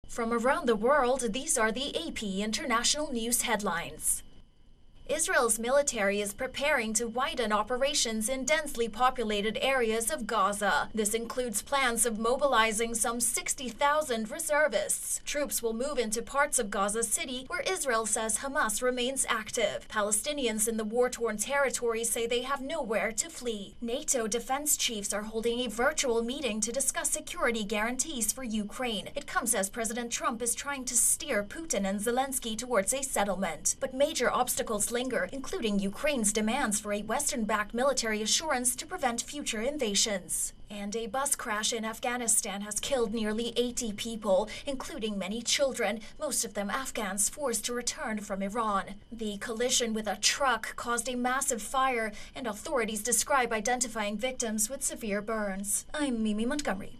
international news headlines